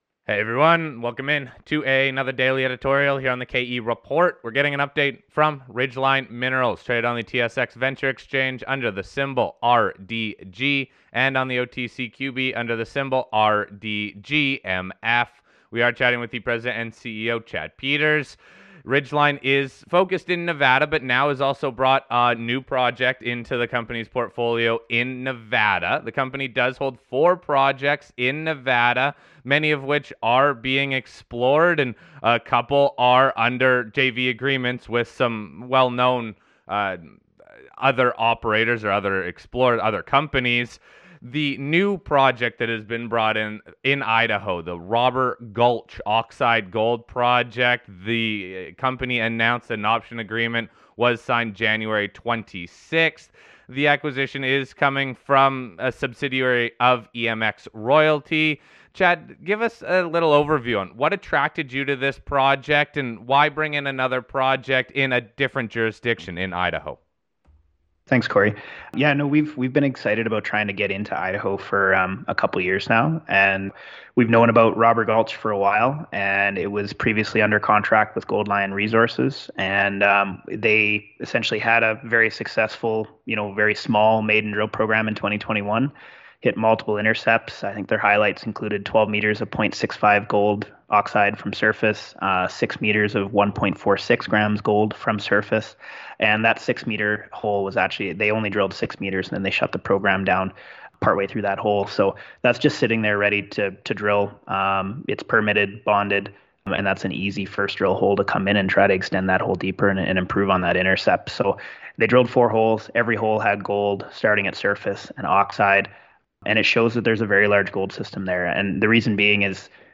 The plan is to do ground work and drill an initial 1,100 meters in 6 shallow holes at this Robber Gulch property this fall.